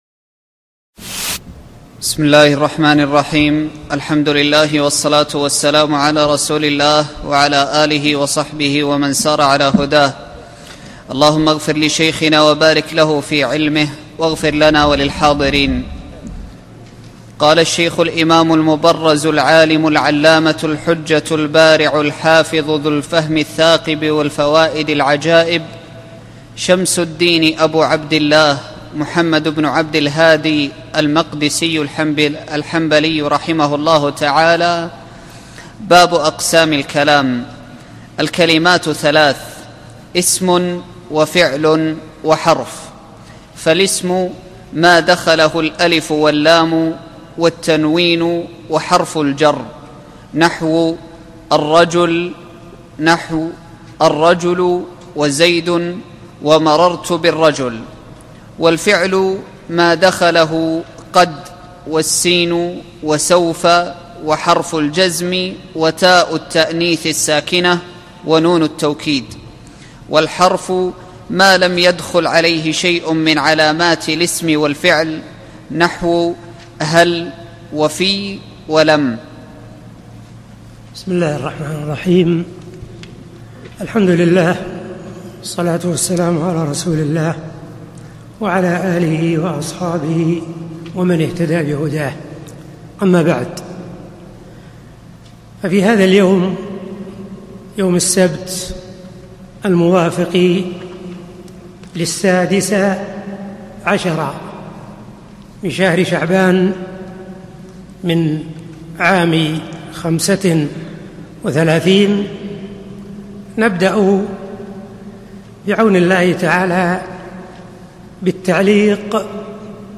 الدورة العلمية 15 المقامة في جامع عبداللطيف آل الشيخ في المدينة النبوية لعام 1435
الدرس الأول